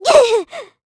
Hilda-Vox_Damage_kr_04.wav